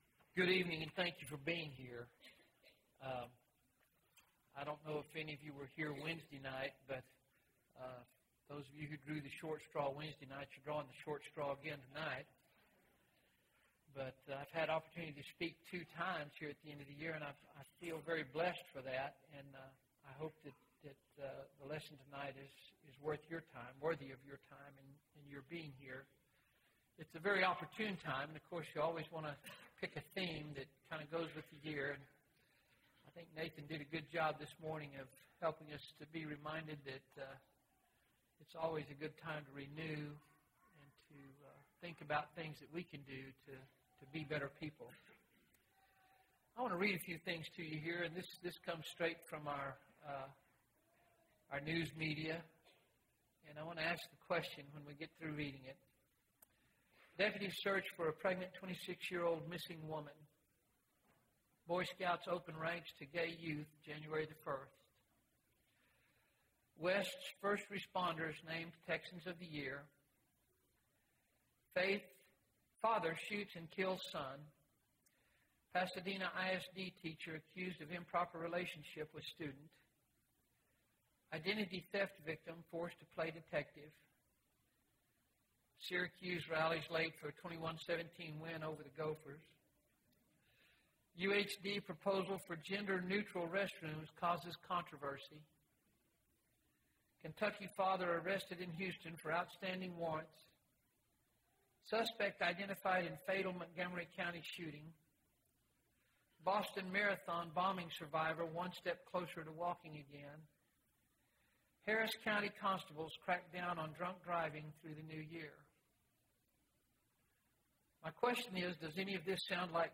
Sermon – Bible Lesson Recording